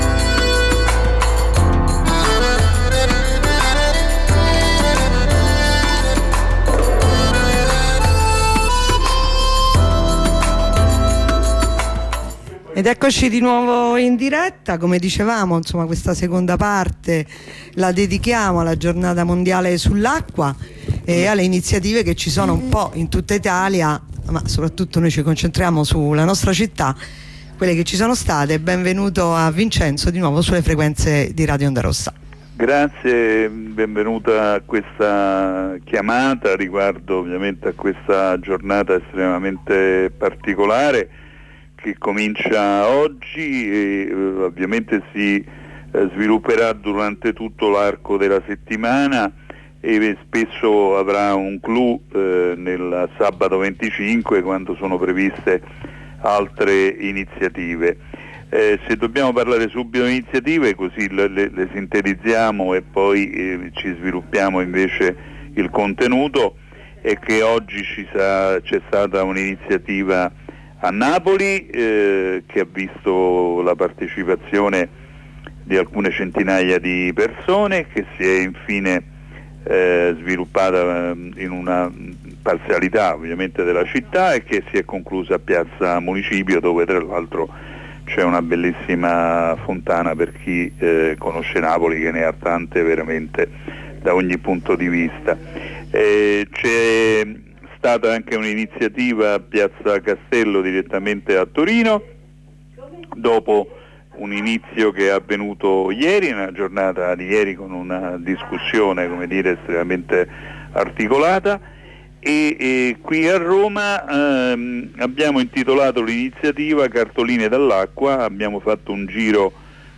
Diretta da La Sapienza